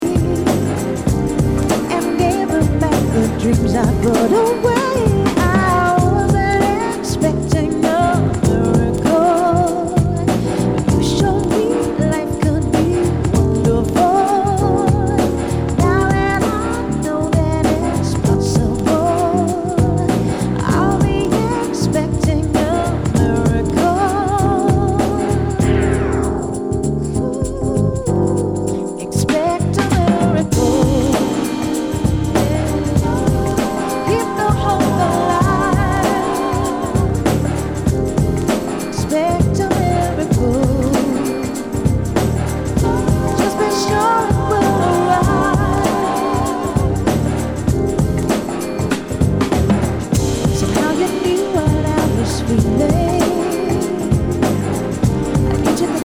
類別 R&B、靈魂樂
HIPHOP/R&B
プレイOKですが盤に歪みあり。全体にチリノイズが入ります